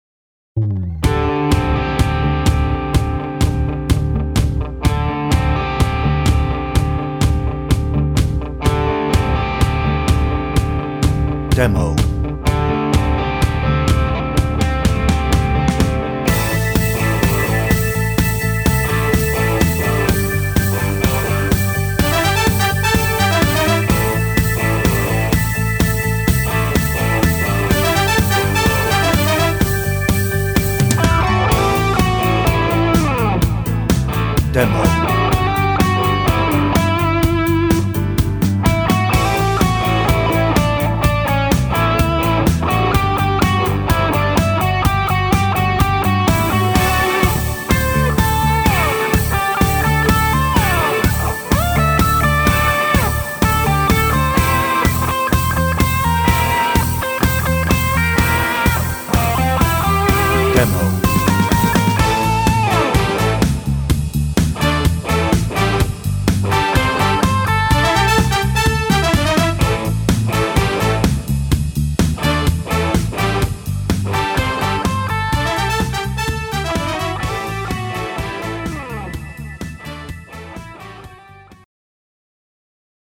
Hoedown - no ref vocal
Instrumental